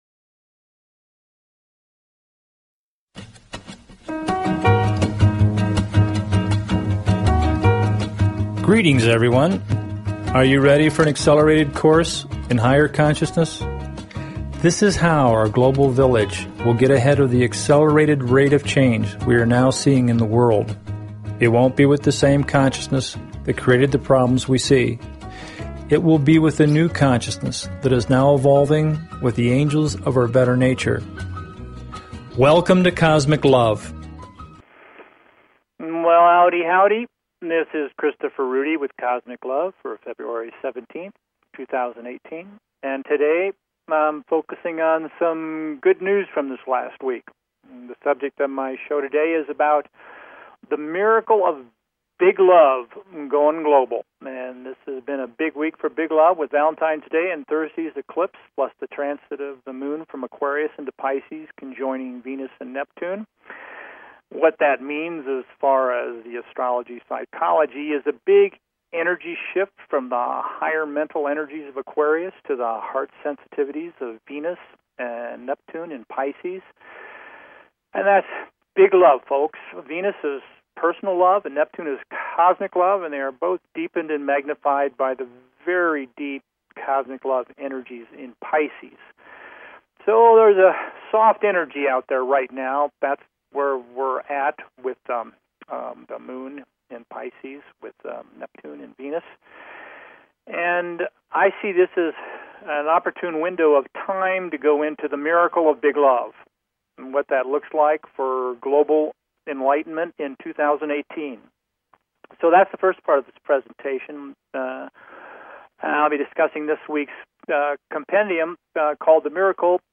This podcast is a partial reading from these articles, plus added insights on the emerging BIG LOVE MIRACLE.